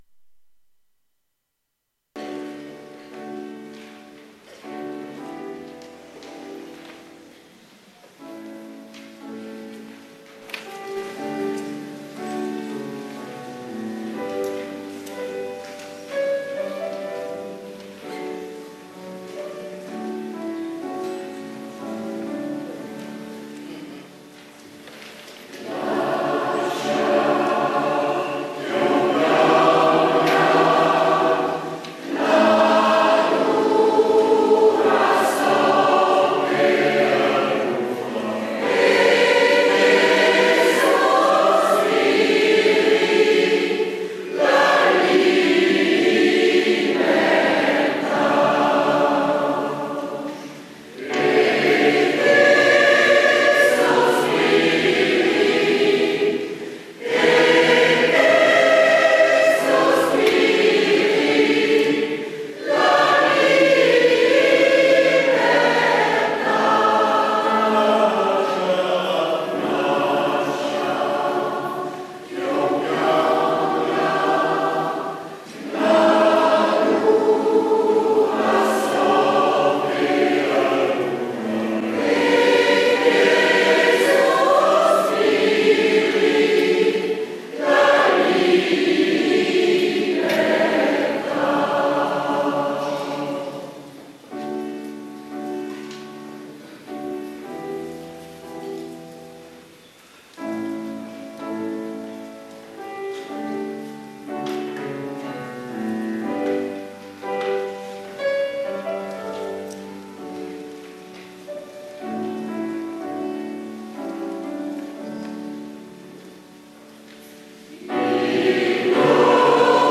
mixte